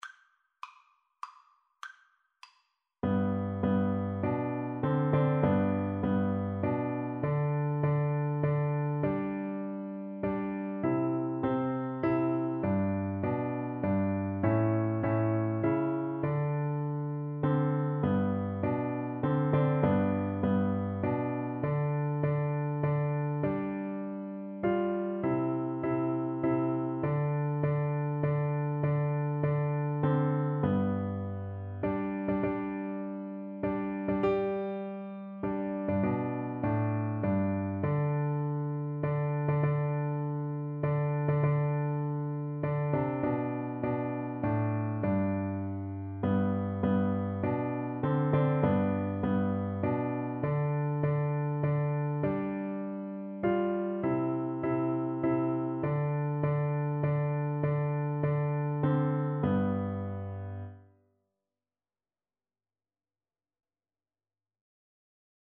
Traditional William H. Doane To God Be the Glory (Doane) Piano Four Hands (Piano Duet) version
3/4 (View more 3/4 Music)
G major (Sounding Pitch) (View more G major Music for Piano Duet )
Traditional (View more Traditional Piano Duet Music)